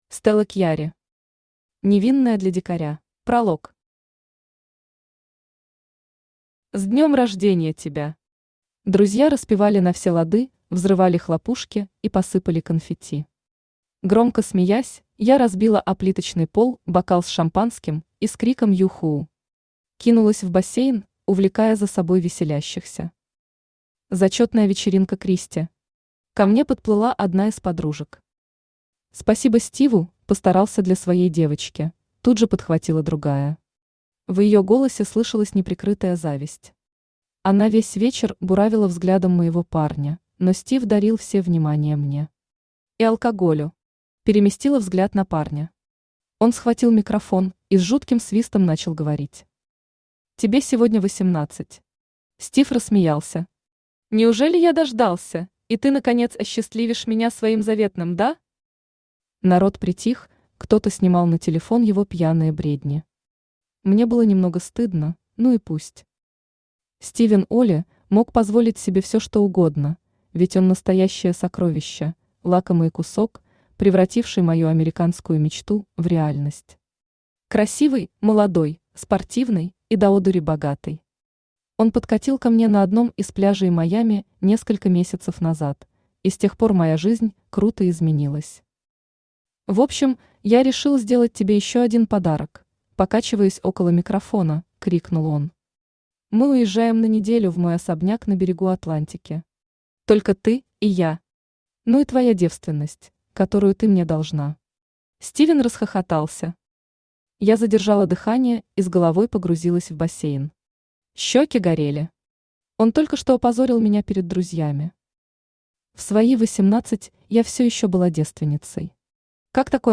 Aудиокнига Невинная для дикаря Автор Стелла Кьярри Читает аудиокнигу Авточтец ЛитРес.